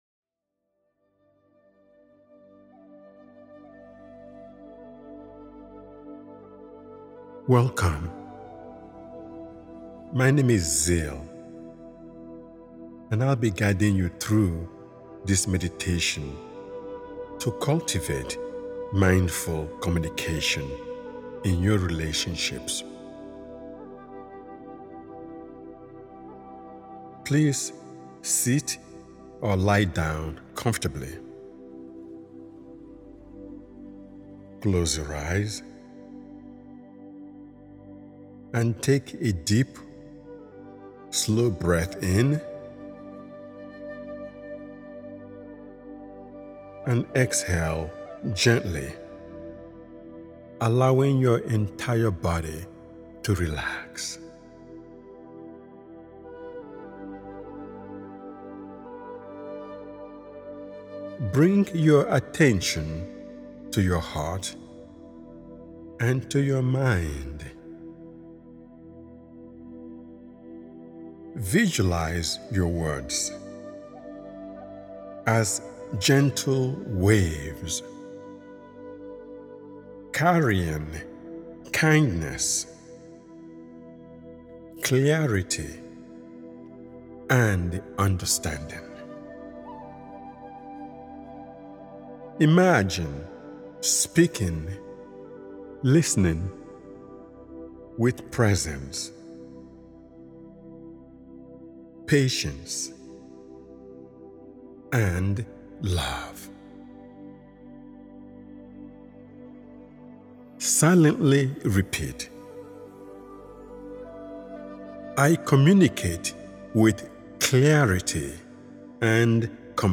Mindful Communication:Bring Peace To Your Relationship A Meditation for Healing Relationships is a gentle yet transformative invitation to restore harmony, clarity, and emotional safety in the way you connect with others.